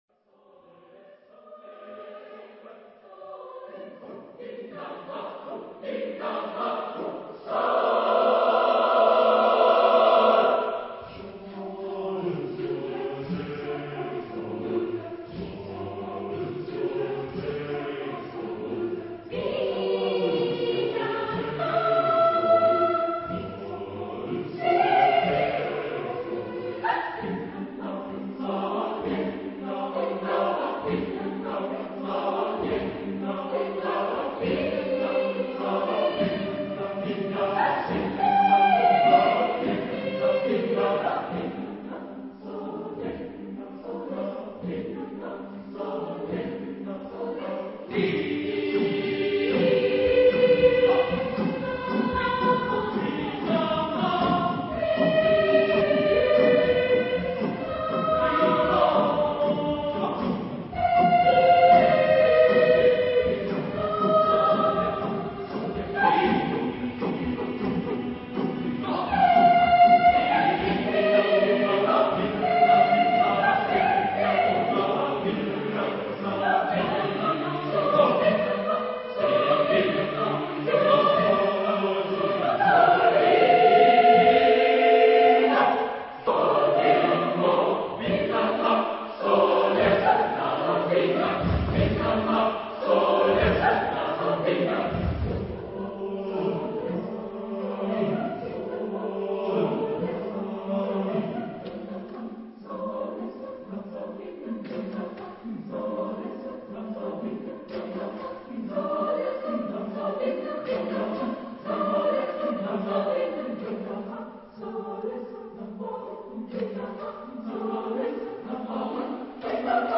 Text in: Spanish + adapted in onomatopoeia
Genre-Style-Form: Contemporary ; Partsong
Type of Choir: SATB  (4 mixed voices )